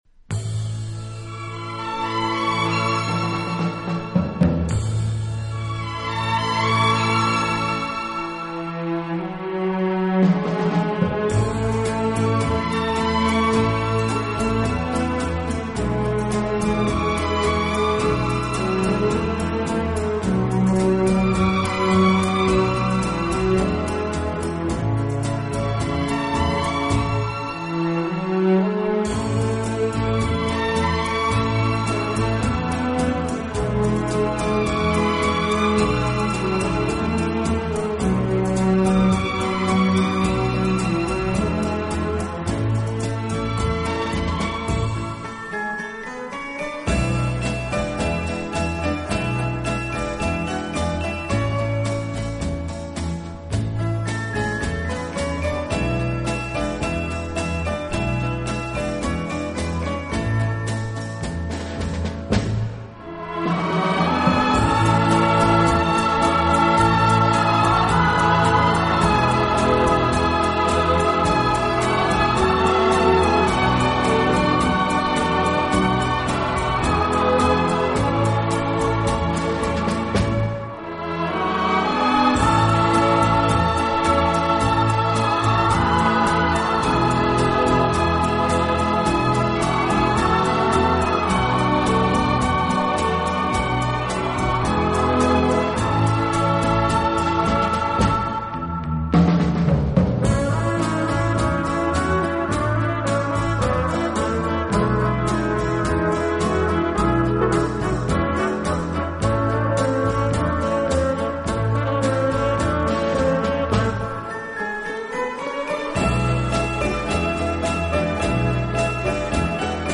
此外，这个乐队还配置了一支训练有素，和声优美的伴唱合唱队。